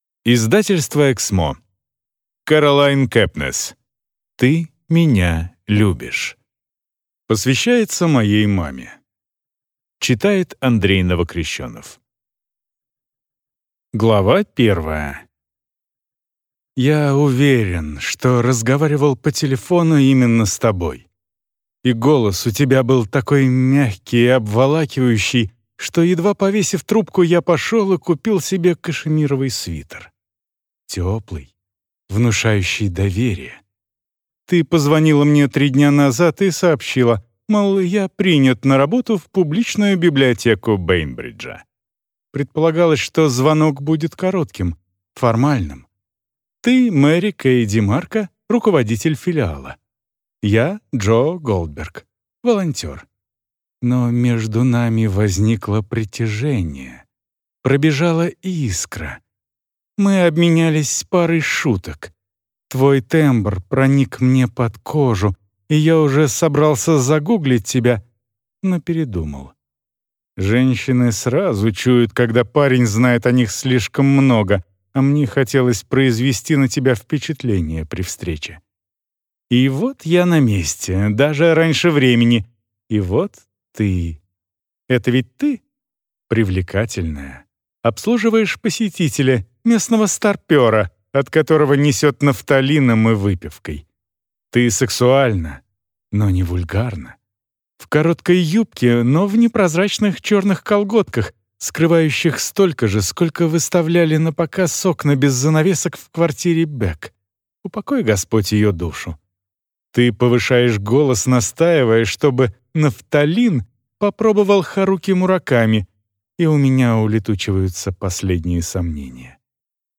Аудиокнига Ты меня любишь | Библиотека аудиокниг